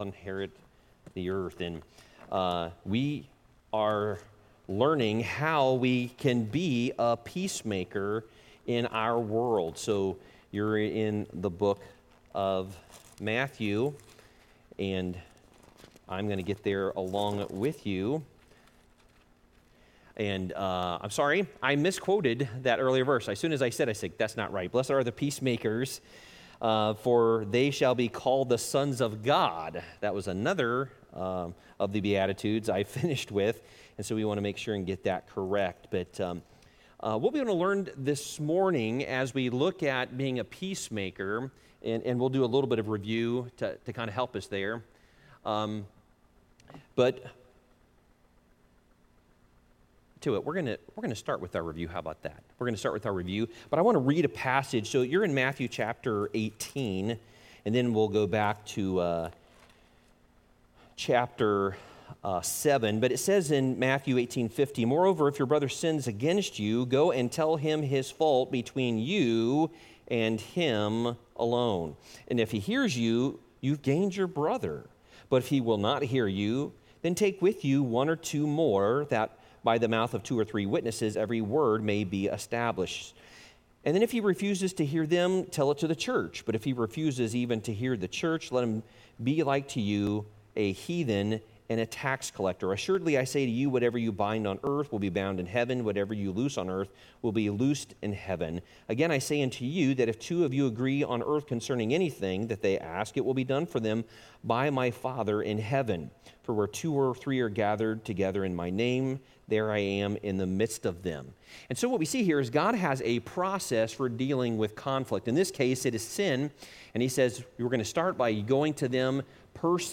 Worship Service 07/16/2023